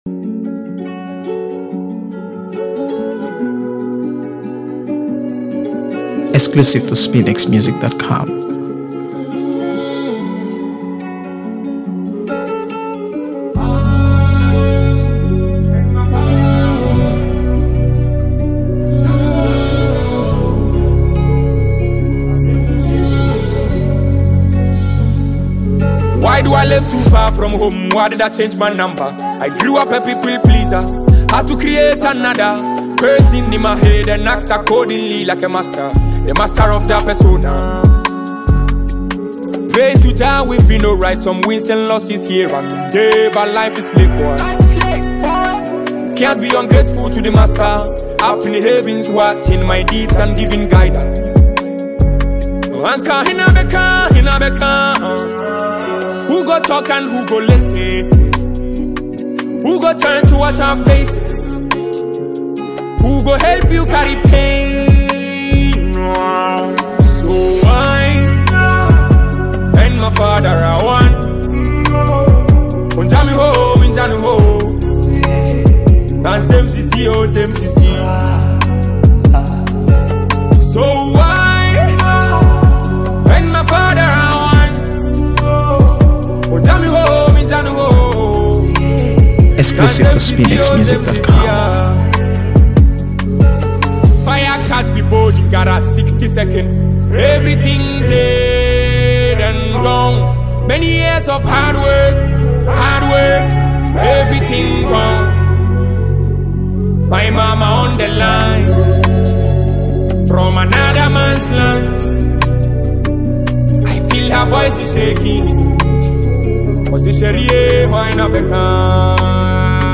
AfroBeats | AfroBeats songs
Ghanaian singer-songwriter and performer